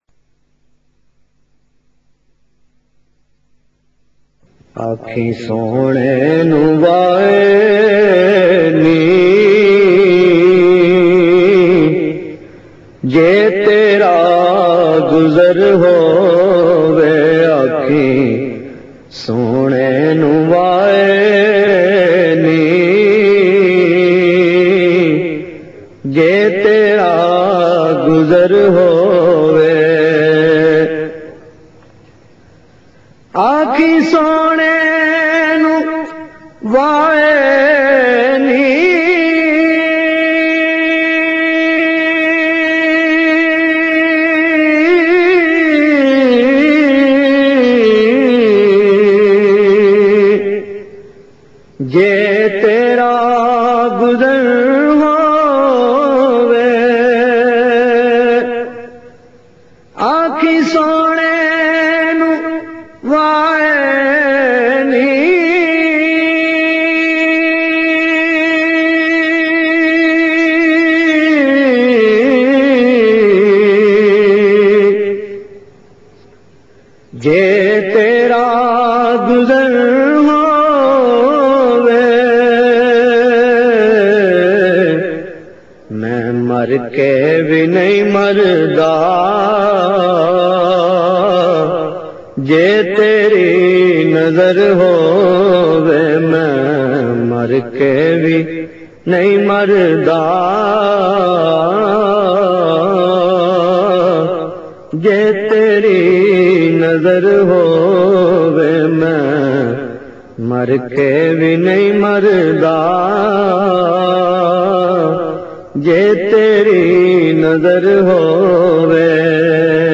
Punjabi Naat
Naat Sharif